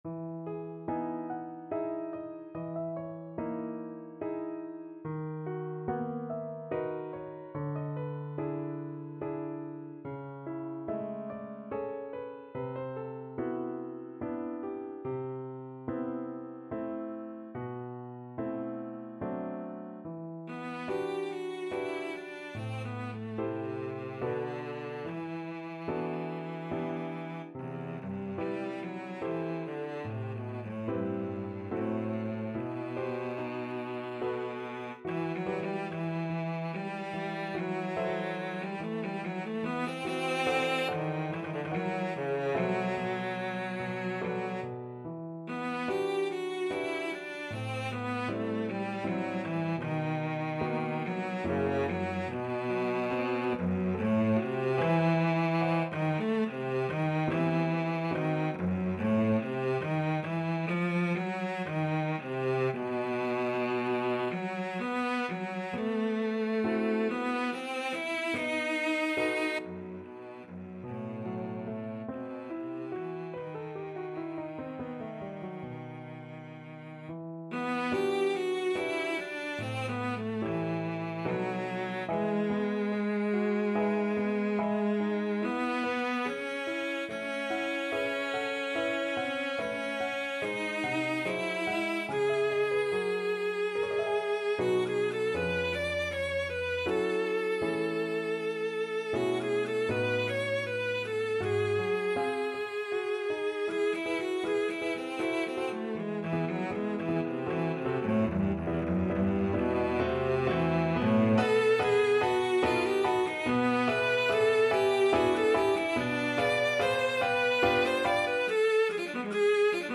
Cello
E minor (Sounding Pitch) (View more E minor Music for Cello )
3/4 (View more 3/4 Music)
Andante =72
Classical (View more Classical Cello Music)